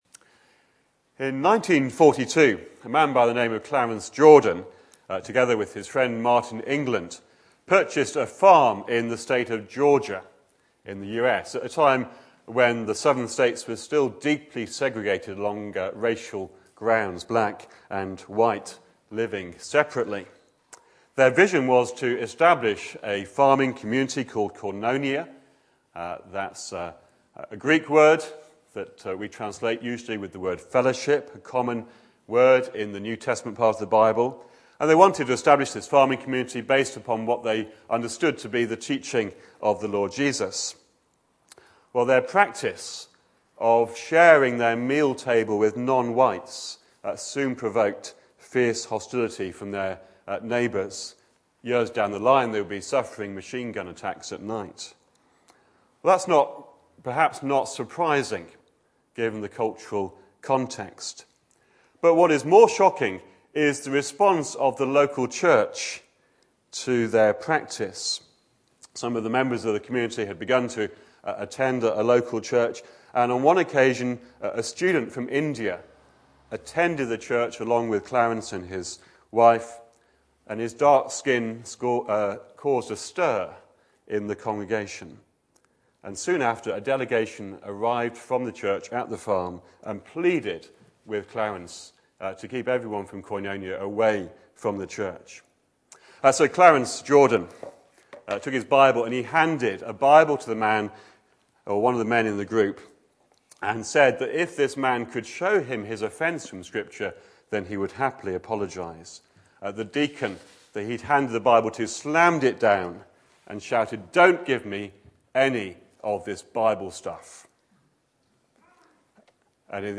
Back to Sermons More harm than good